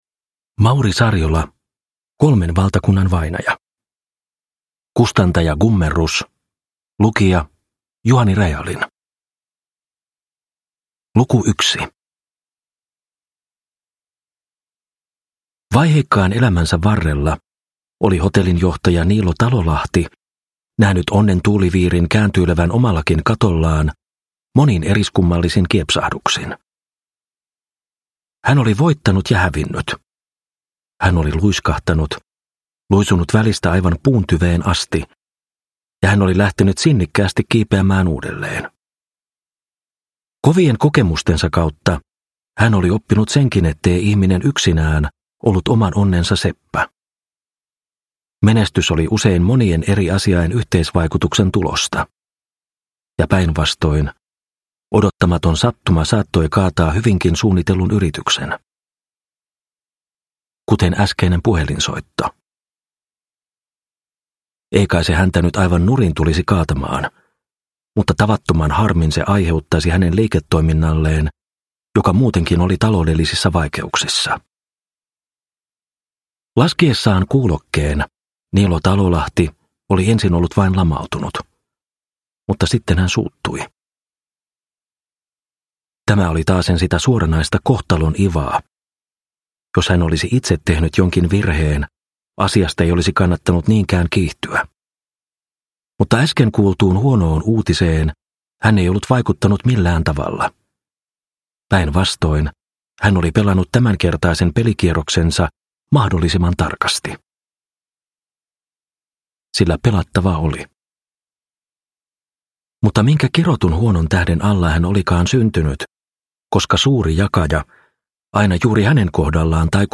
Kolmen valtakunnan vainaja – Ljudbok – Laddas ner
Uppläsare